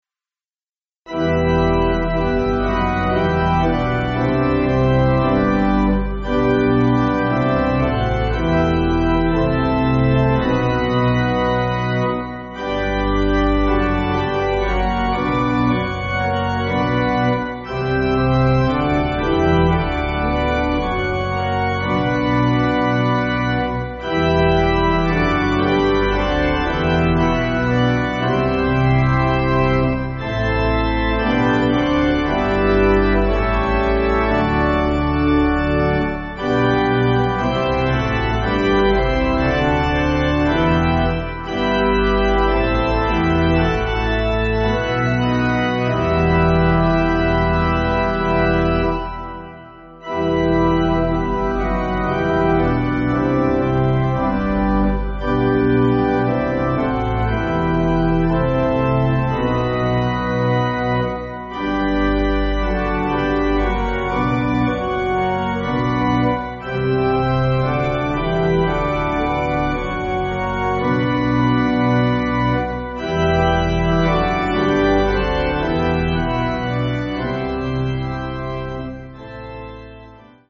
Organ
(CM)   5/Em